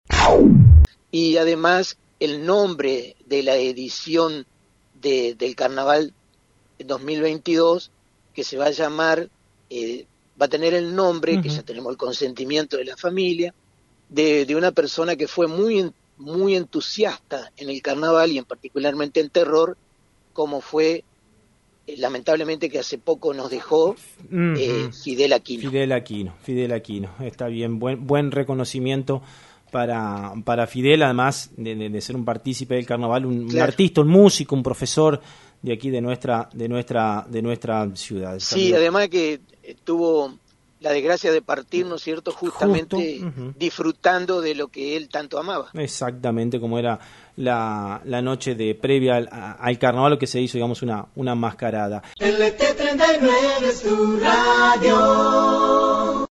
En diálogo con FM 90.3, el intendente Domingo Maiocco confirmó que mañana, en conferencia de prensa, se informarán de los detalles de la edición 2022 de la fiesta mayor de la ciudad.